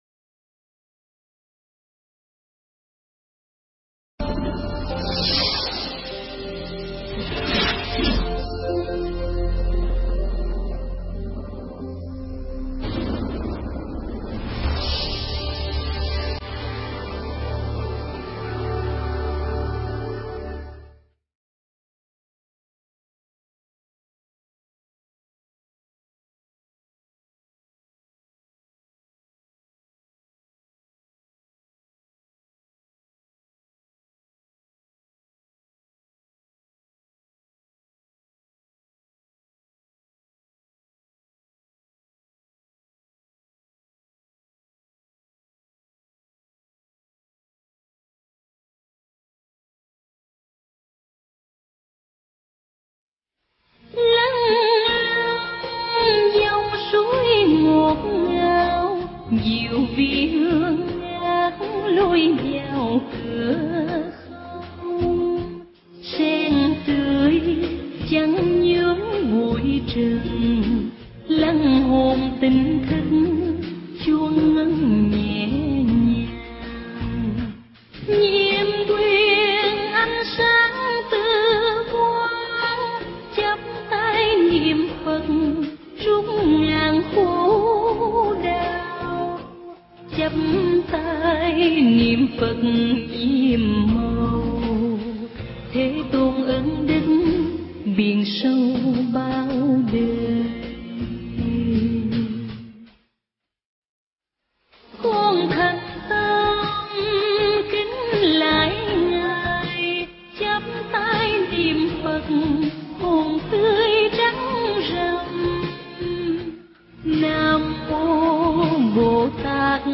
Nghe Mp3 thuyết pháp Bảy Phương Pháp Bố Thí